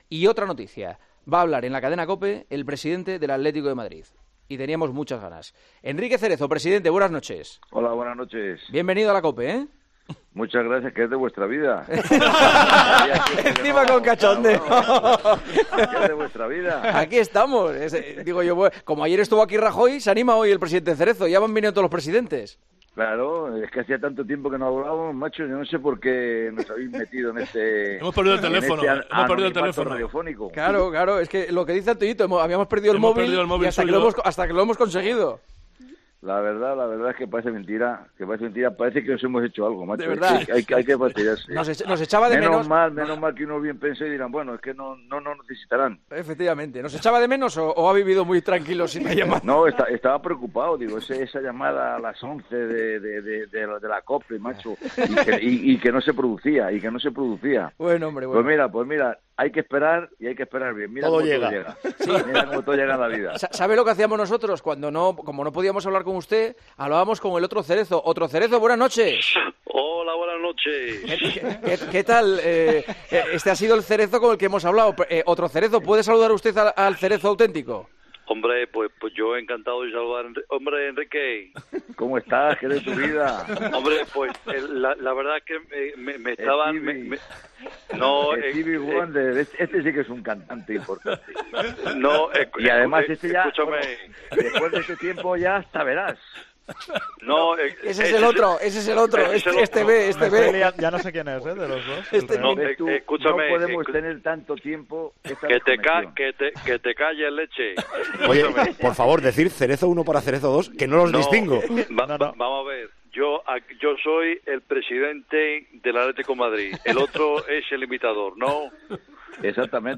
El presidente del Atlético de Madrid atendió este miércoles la llamada de El Partidazo de Cope, donde contó detalles sobre la llegada de una nueva...